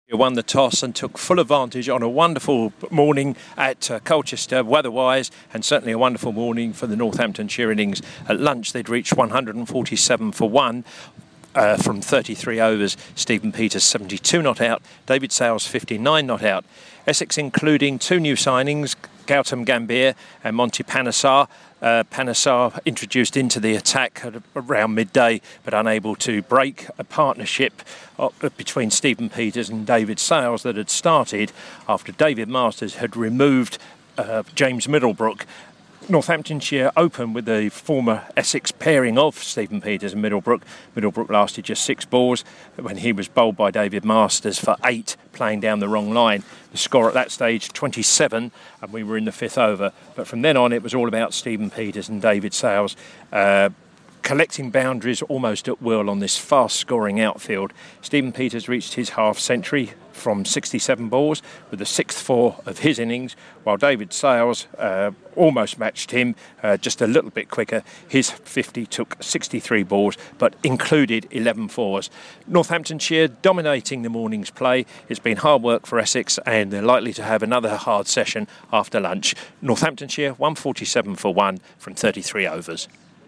Lunch time report from Castle Park as Essex take on Northamptonshire at the Colchester Cricket Festival